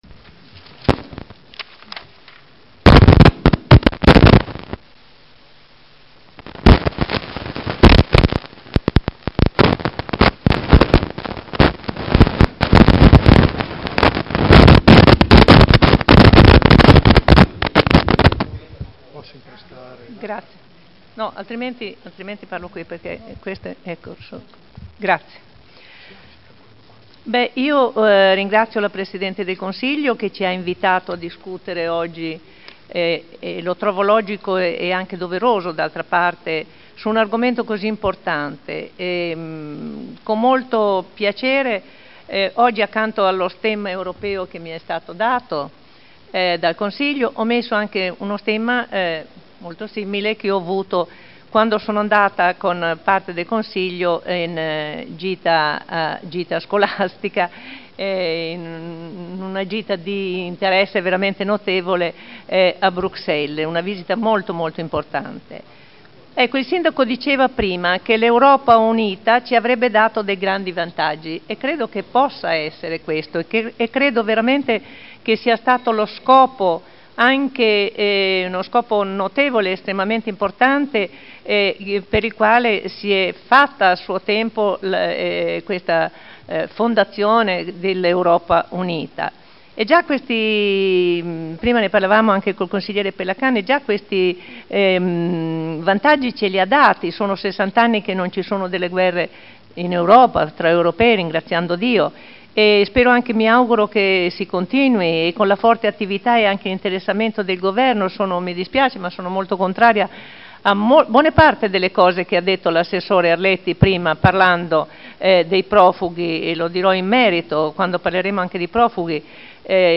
Seduta del 09/05/2011. Dibattito sugli Ordini del Giorno sull'Europa